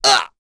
Ezekiel-Vox_Damage_02.wav